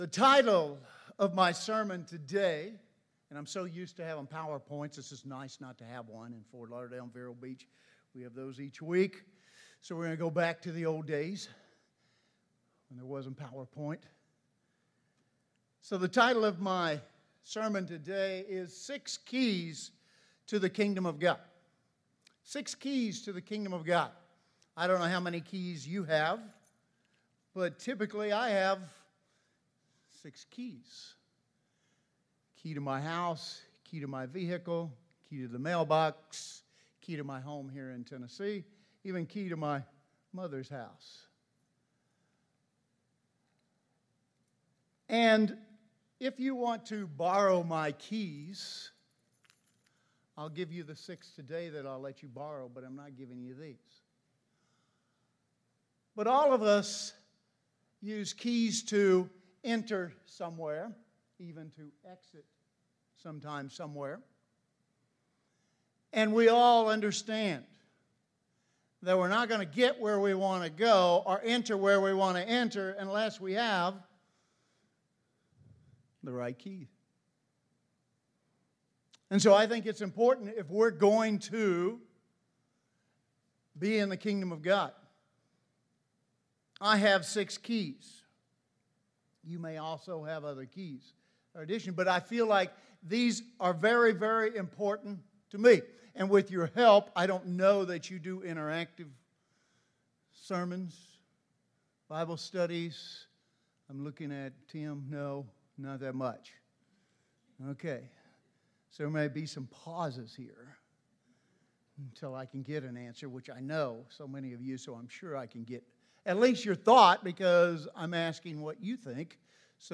This message dives into 6 keys for pursuing the kingdom of God.